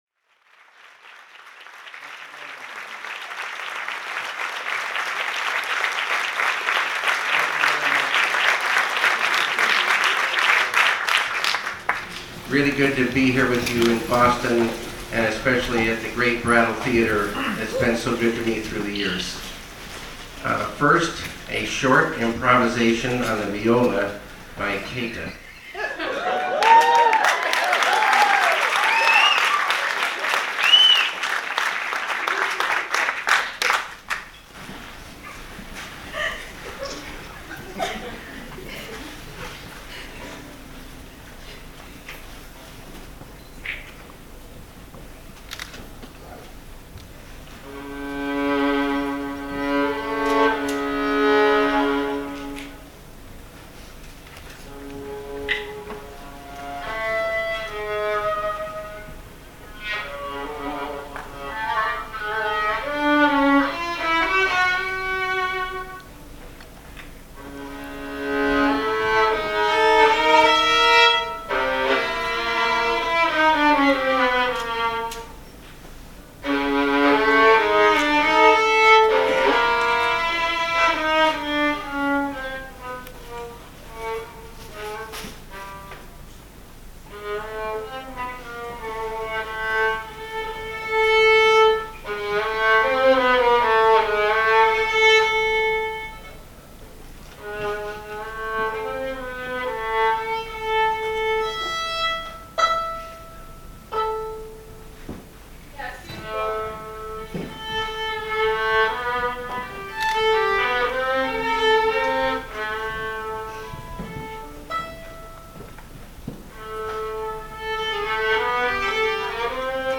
Here’s David Lynch’s question & answer session with the Brattle audience after the credits rolled, as well as a pre-film intro that included some improvisational viola and a bit of poetry…
David Lynch @ the Brattle Theatre